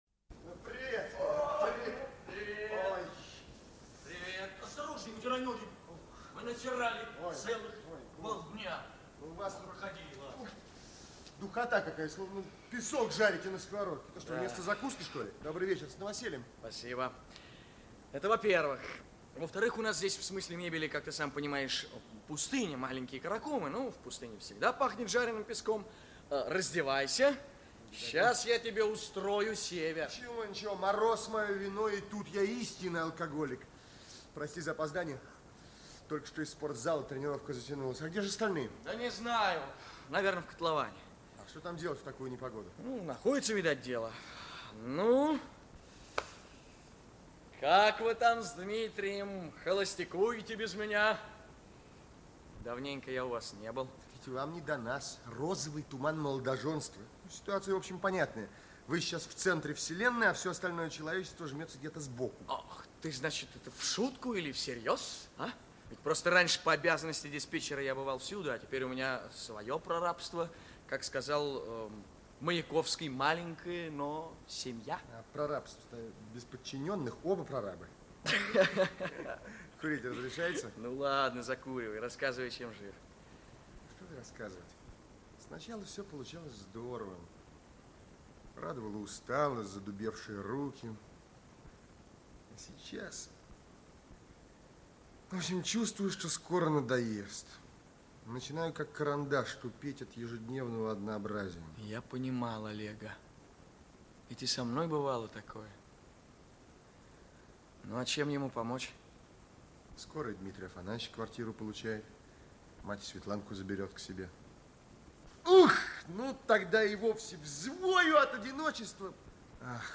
Часть 2 Автор Евгений Петрович Карпов Читает аудиокнигу Олег Табаков.